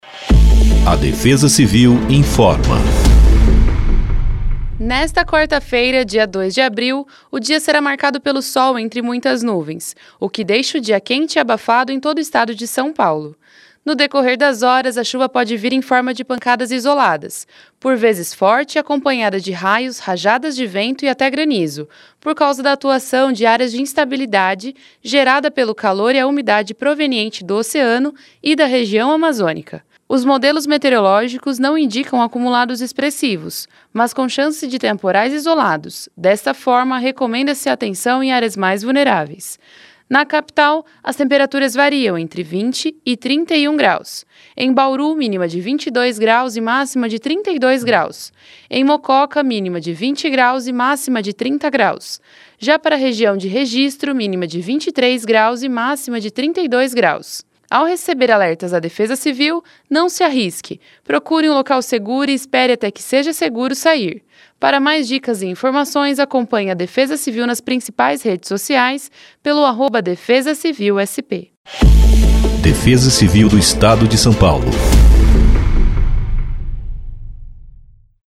Defesa-Civil-Boletim-Previsao-do-Tempo-para-0204-Spot.mp3